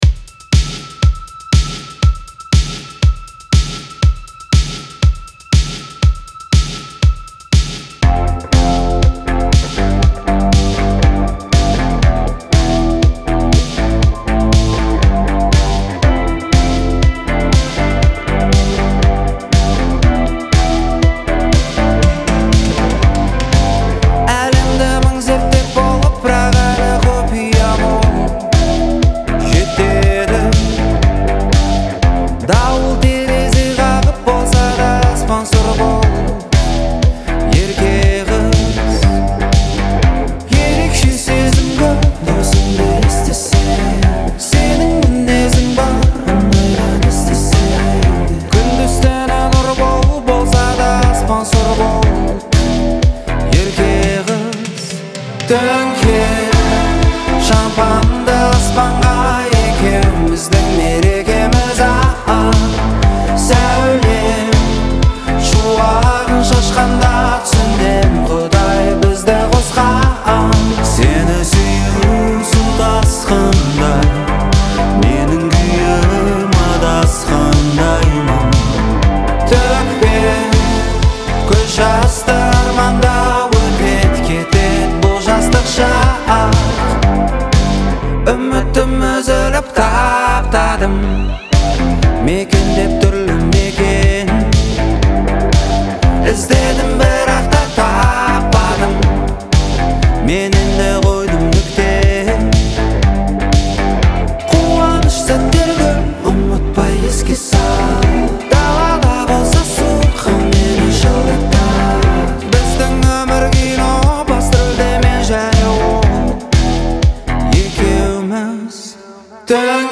Жанр: qazaqpop